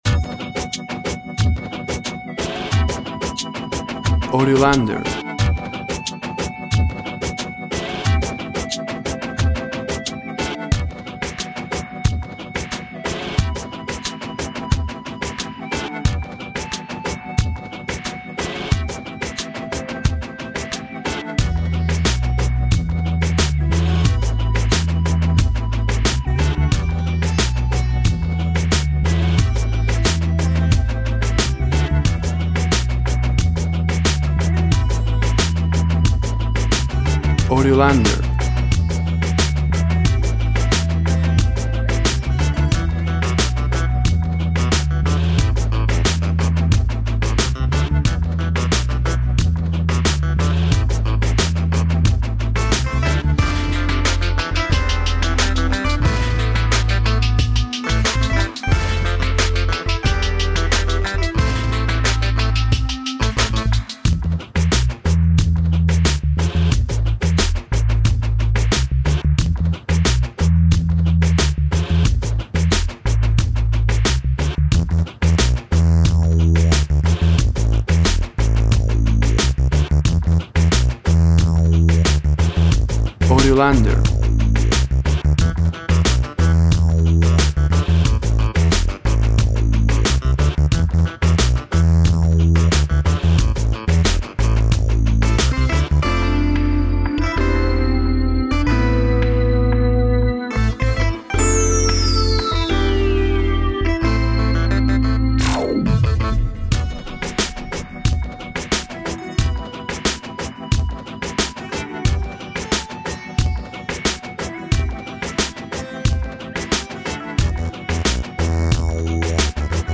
Instrumental Reggae with electronic instruments.
WAV Sample Rate 16-Bit Stereo, 44.1 kHz
Tempo (BPM) 90